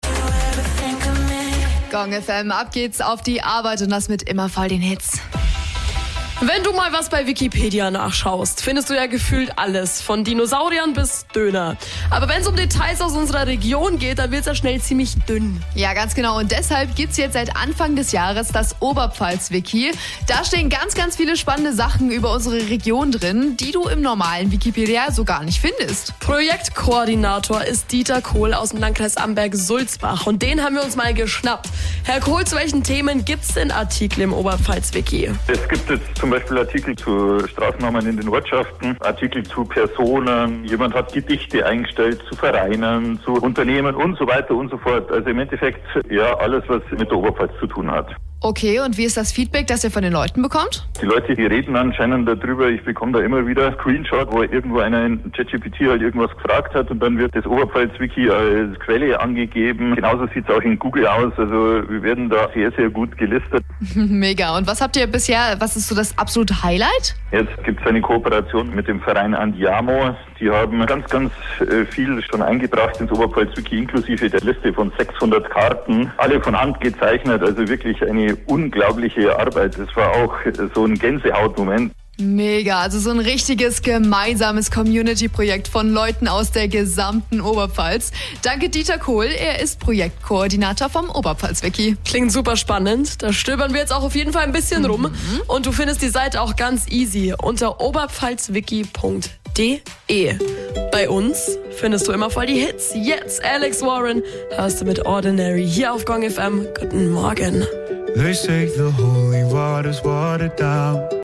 Interview wurde telefonisch geführt